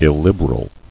[il·lib·er·al]